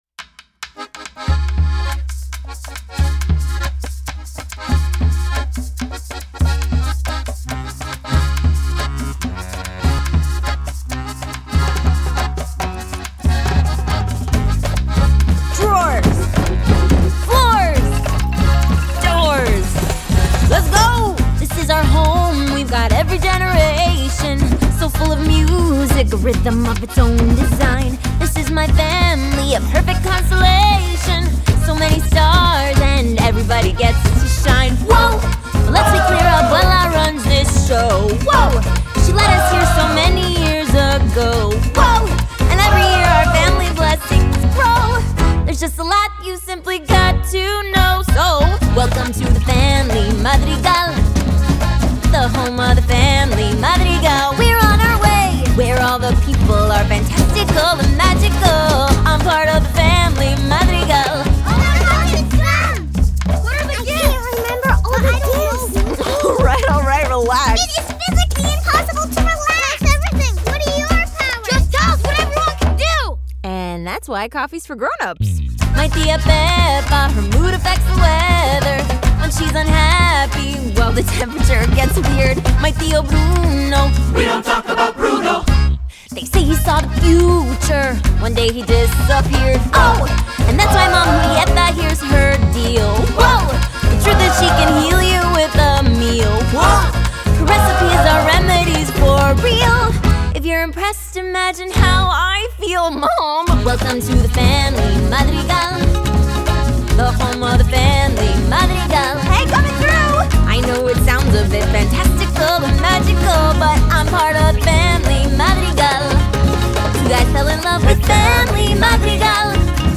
Soundtrack, Latin Pop